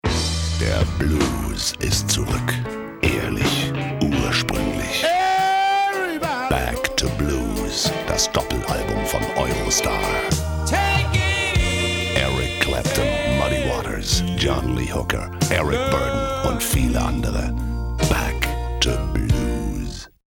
Kein Dialekt
Sprechprobe: Werbung (Muttersprache):
german voice over artist